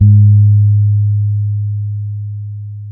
-MM DUB  G#3.wav